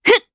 Valance-Vox_Jump_jp.wav